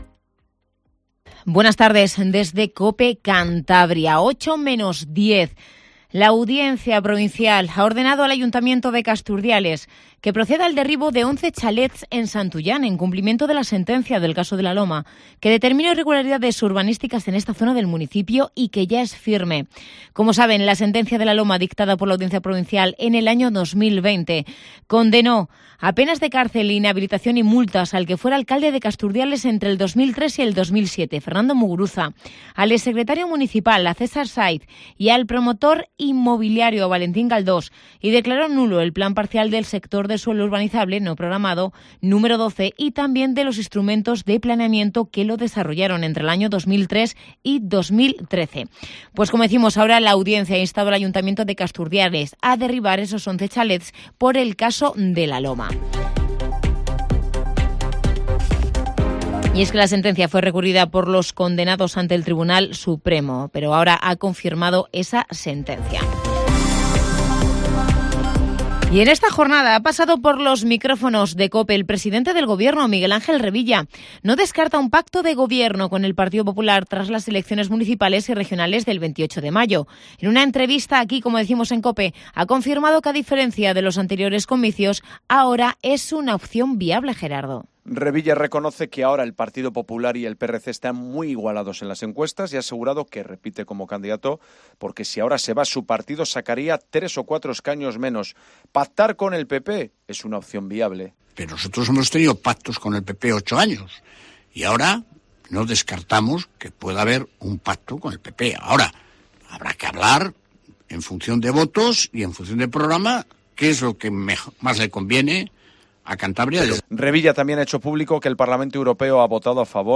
Informativo Tarde